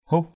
/huh/ (d.) làng Thái Hòa (Mỹ Tường) = village de Thái-hòa.